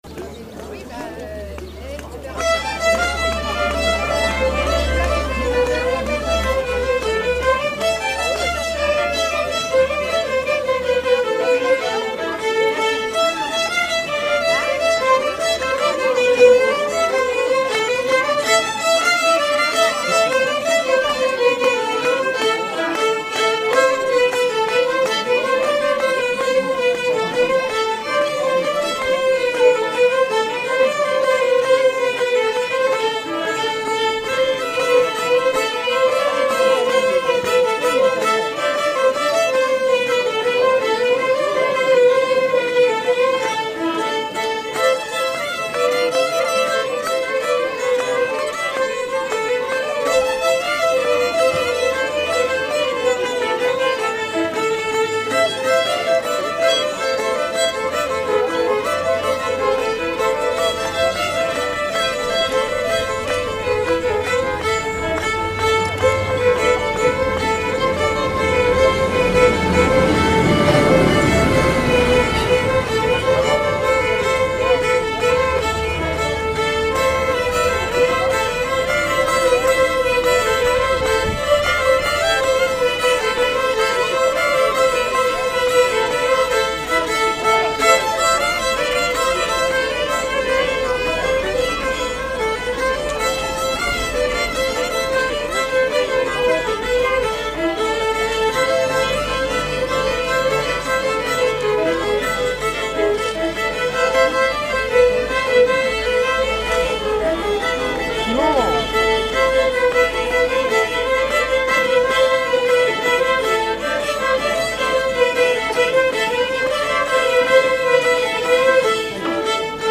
05_polka-euro-est-violons.mp3